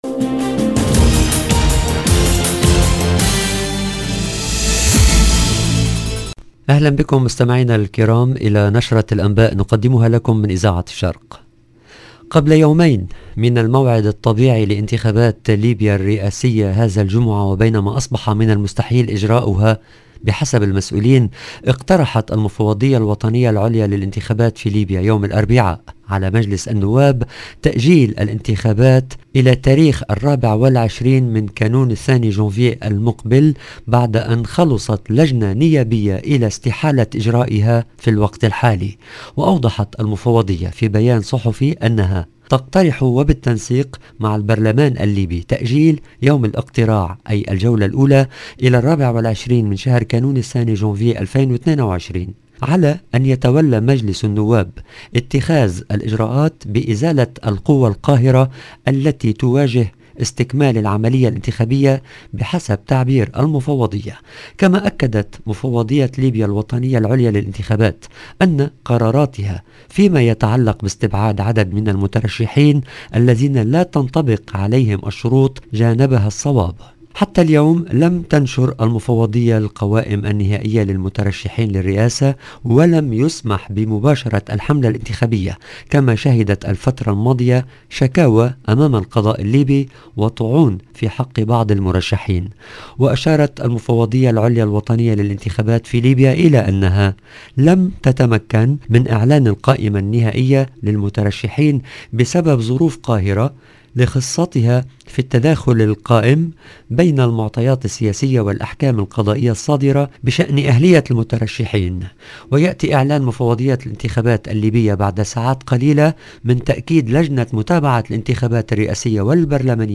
LE JOURNAL DU SOIR EN LANGUE ARABE DU 22/12/2021
EDITION DU JOURNAL DU SOIR EN LANGUE ARABE DU 22/12/2021